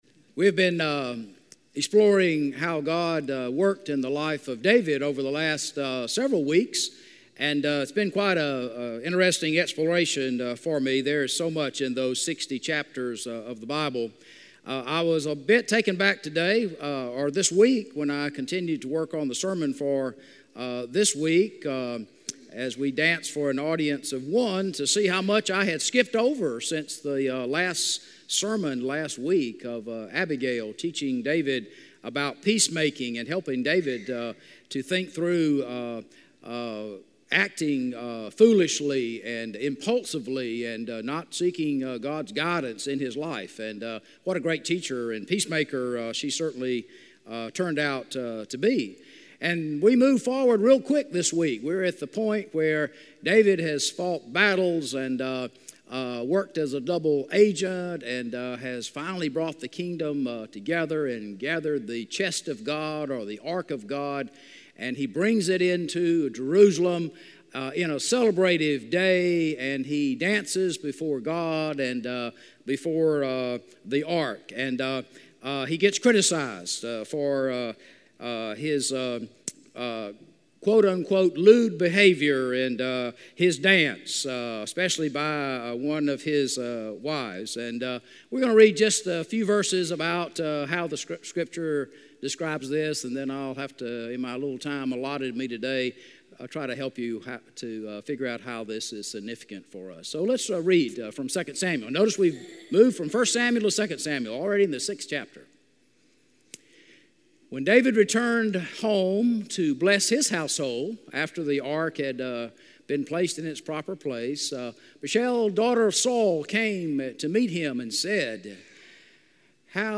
A message from the series "The Story of David."